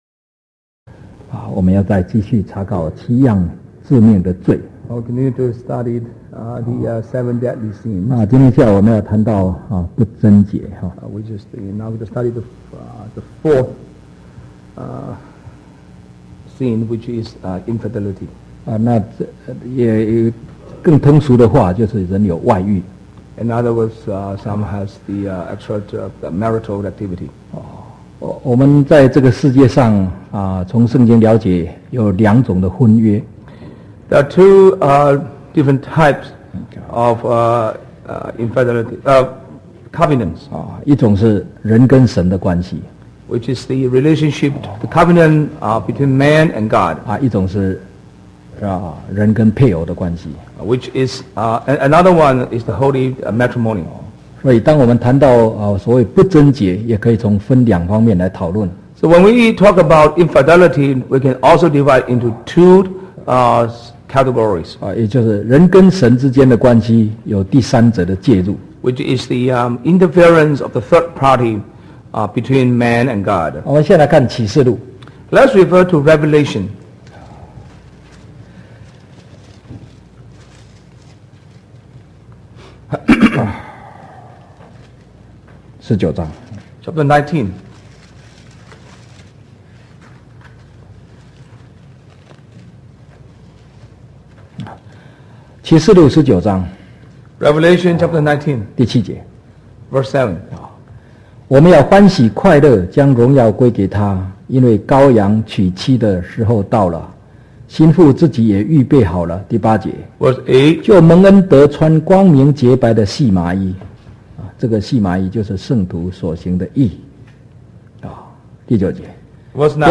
TJC True Jesus Church audio video sermons Truth Salvation Holy Spirit Baptism Foot Washing Holy Communion Sabbath One True God